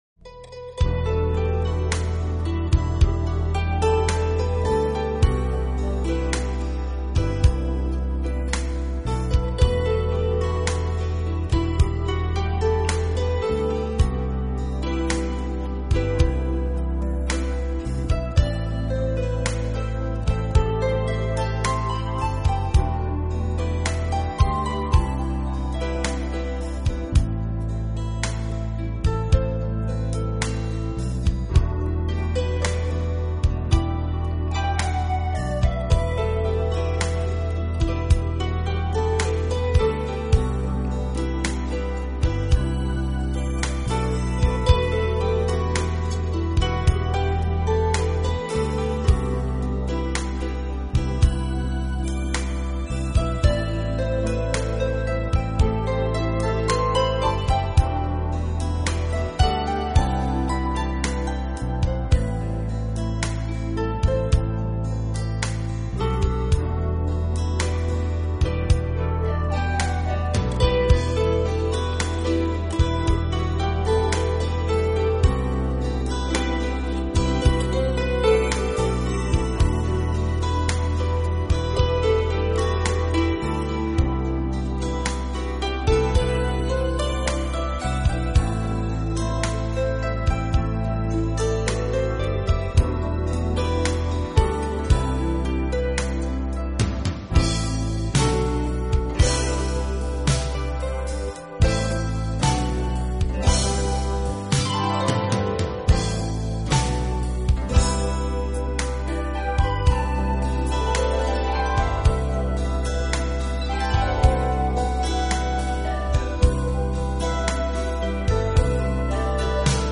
【纯音乐】
我们需要做的就是聆听抒缓音乐的极品。